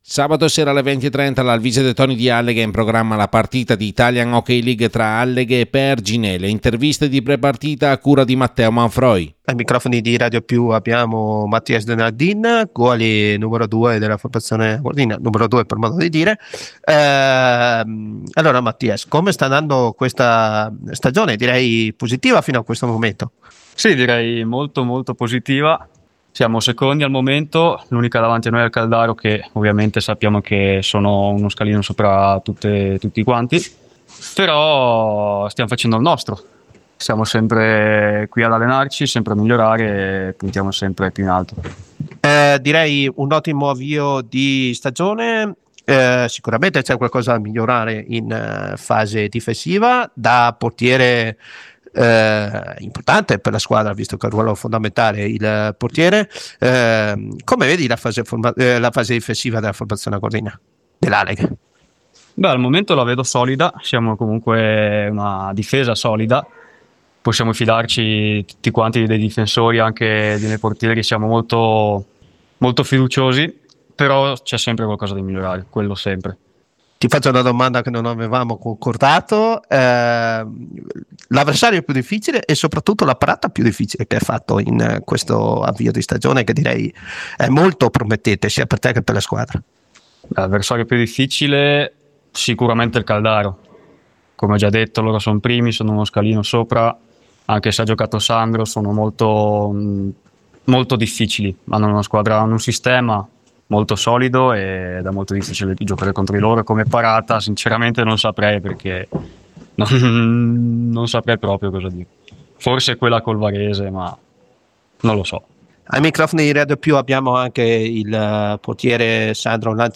INTERVISTE PRE PARTITA ALLEGHE – PERGINE